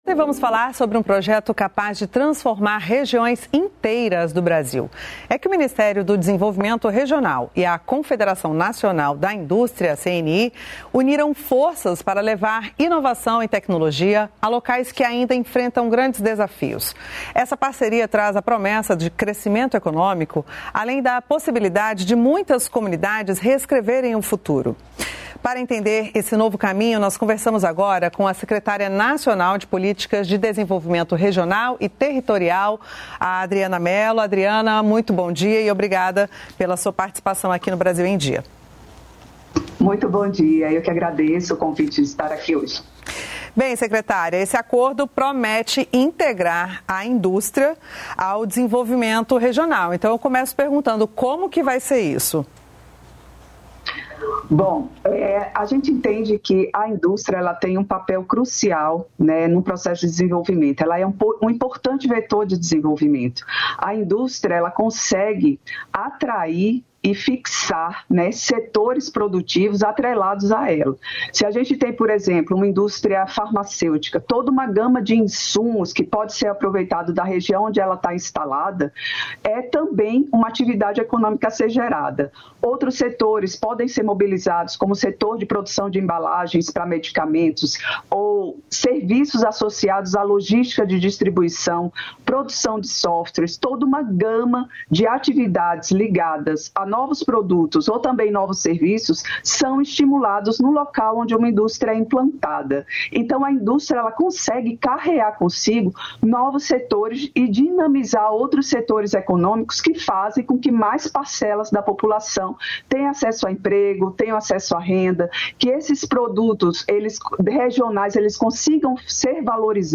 Brasil em Dia - Entrevista